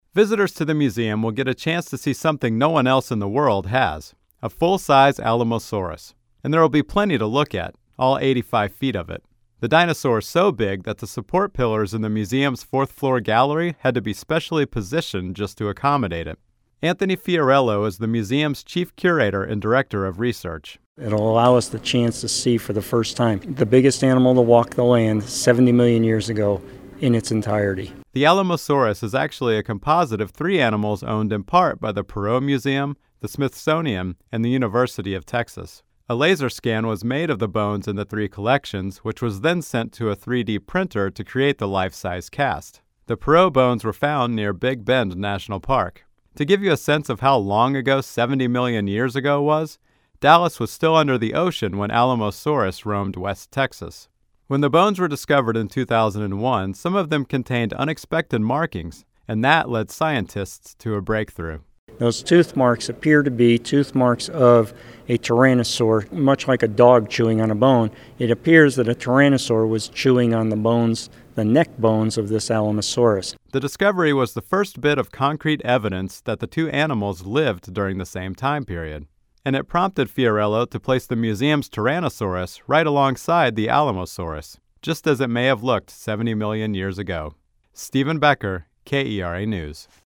KERA Radio story: